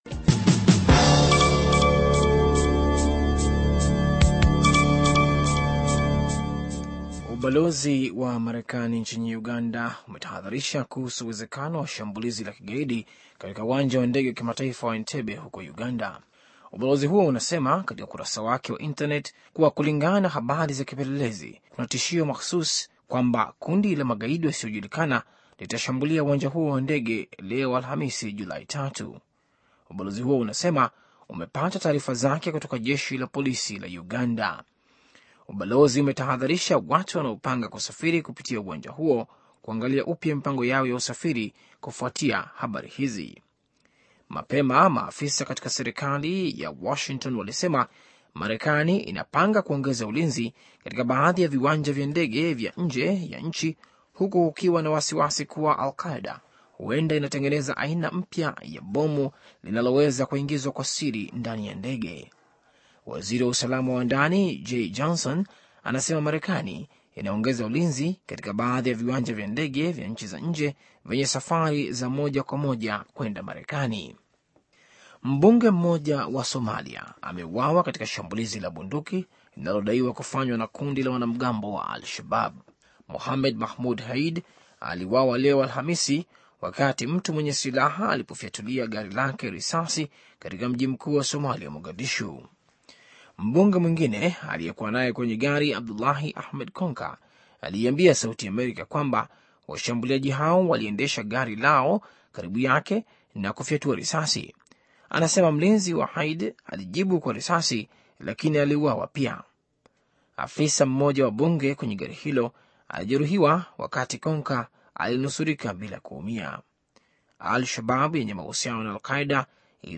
Taarifa ya Habari VOA Swahili - 6:52